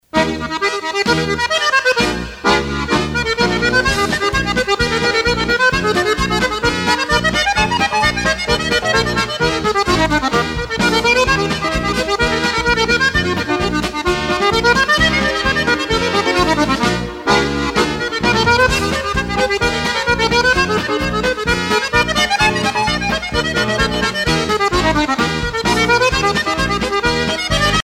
danse : mazurka
Pièce musicale éditée